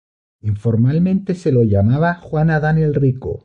Pronunciado como (IPA)
/aˈdan/